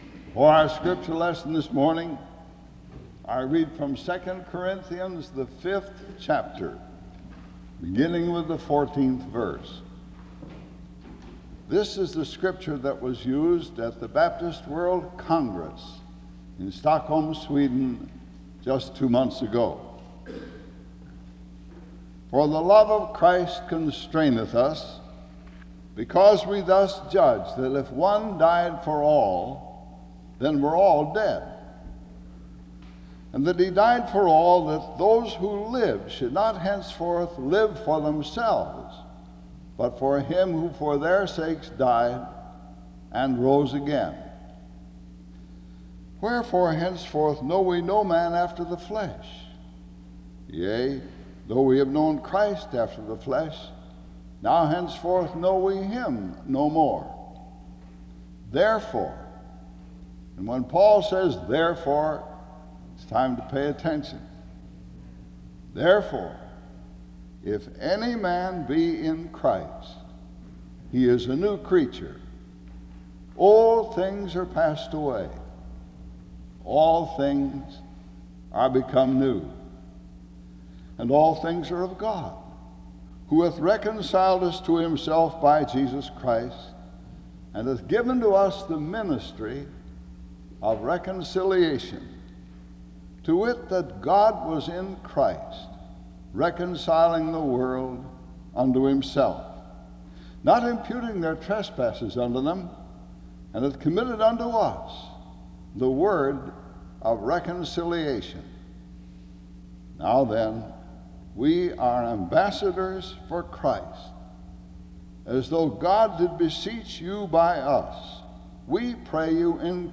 The service starts with 2 Corinthians 5:14-20 being read from 0:00-2:07. A prayer is offered from 2:08-3:30. Music plays from 3:42-5:52.